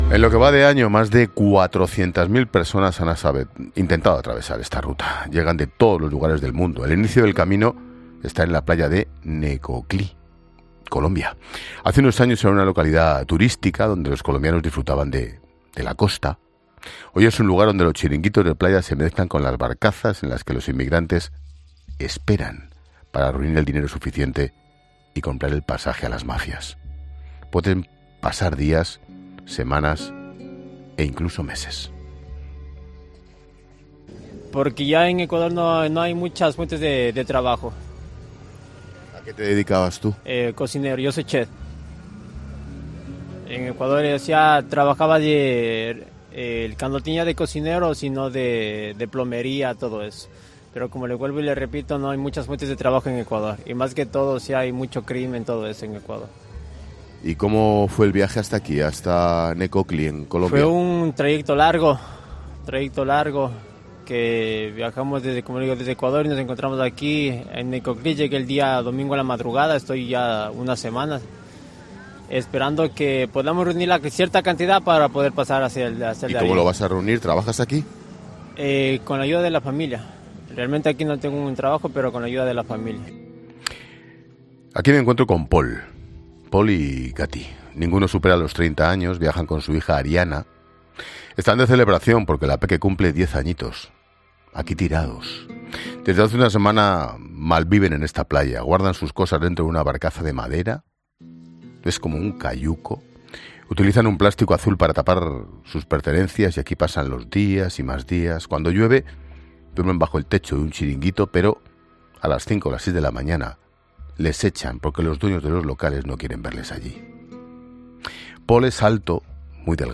Ángel Expósito, con parte de su equipo, ha viajado hasta la selva que une Colombia con Panamá.
En un programa especial, el director del programa ha descrito cómo es esta ruta migratoria, cuáles son los peligros que los migrantes se encuentran en el camino y por qué lo hacen.